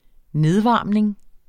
Udtale [ -ˌvɑˀmneŋ ]